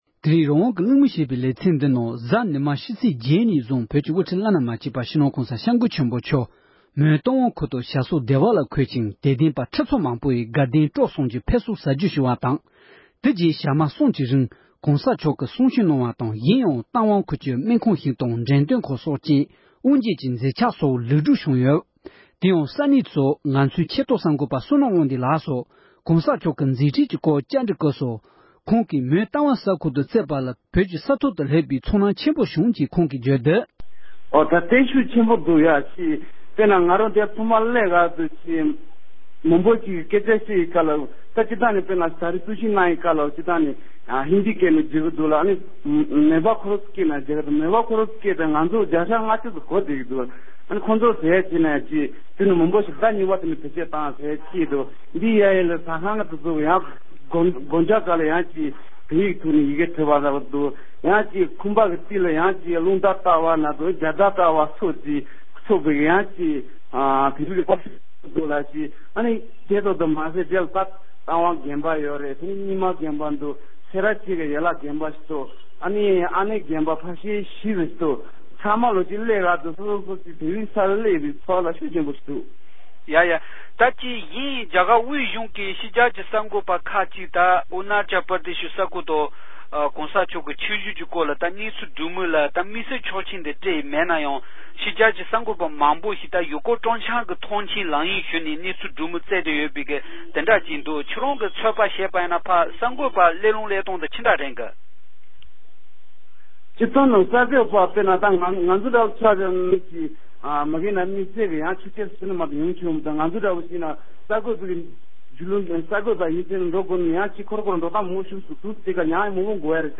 སྒྲ་ལྡན་གསར་འགྱུར།
གནས་འདྲི